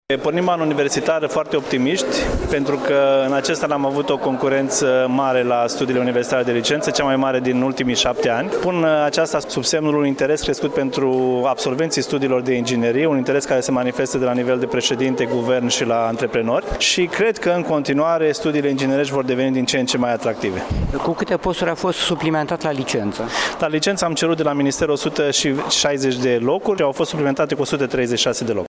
Astăzi, s-a deschis anul academic la Universitatea Tehnică Gheorghe Asachi din Iaşi, în prezența oficialităţilor locale şi judeţene.